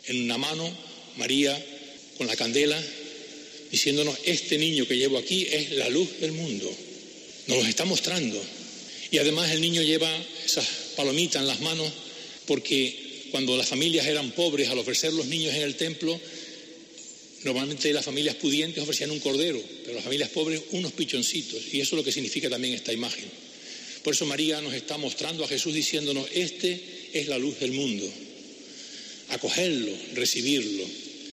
Obispo Bernardo Álvarez homilía en Candelaria